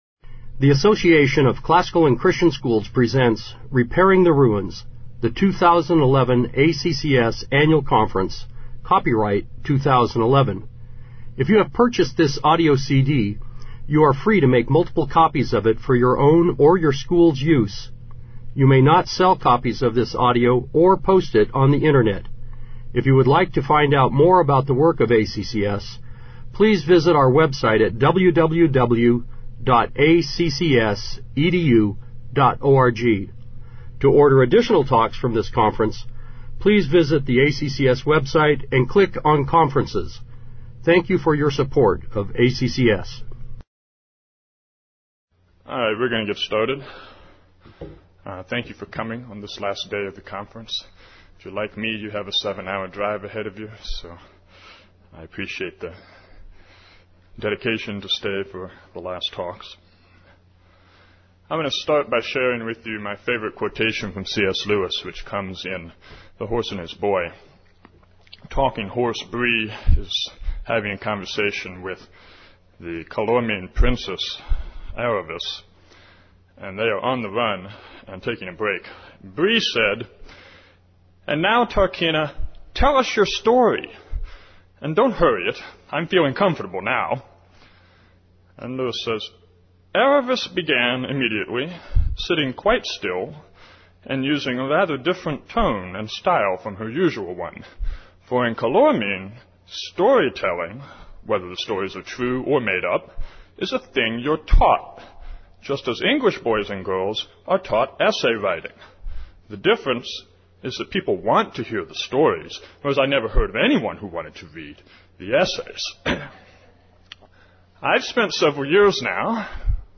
2011 Workshop Talk | 0:57:31 | 7-12, Rhetoric & Composition